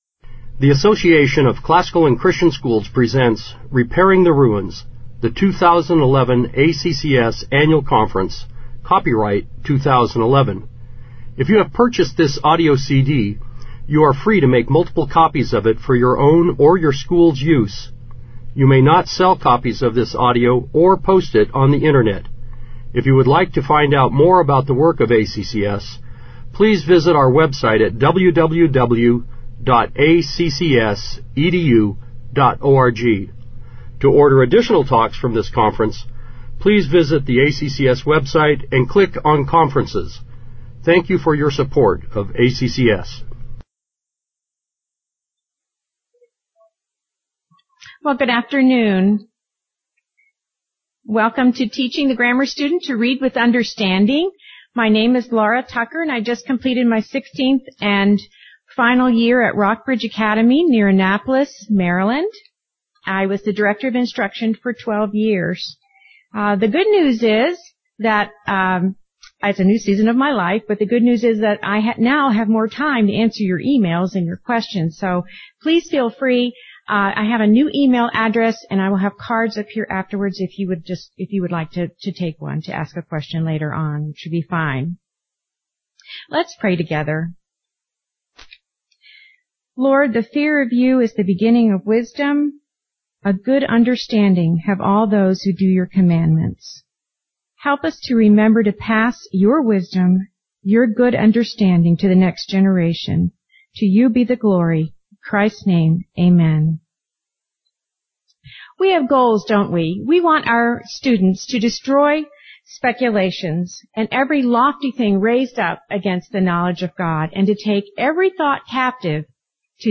2011 Workshop Talk | 0:58:13 | K-6, Literature